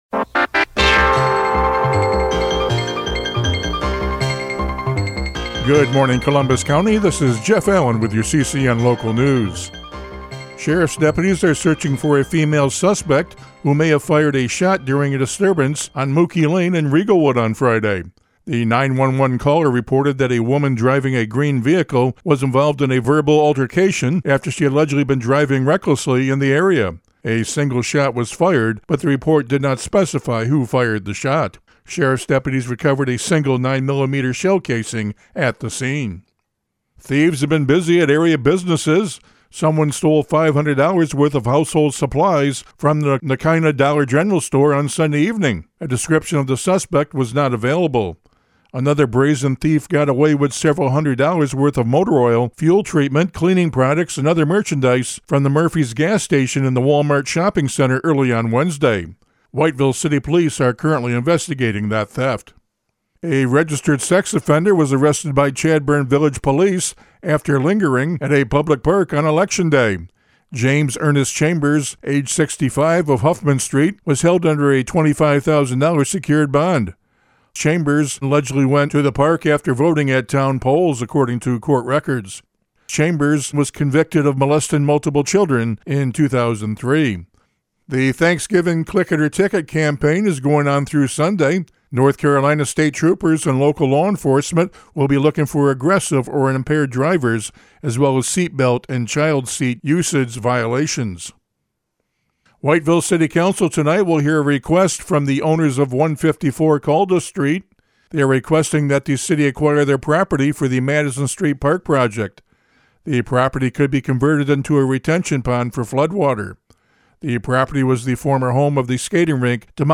CCN Radio News — Morning Report for November 25, 2025